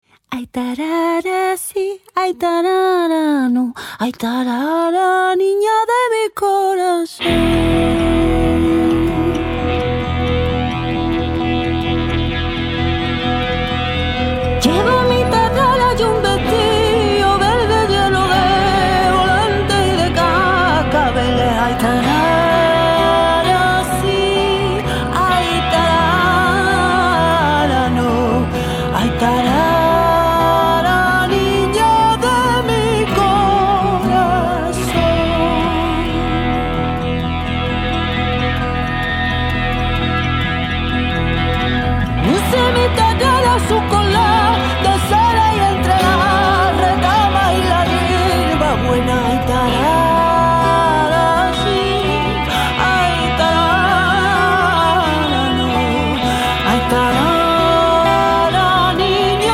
guitarra
a momentos sutil y delicada y a otros cruda e incisiva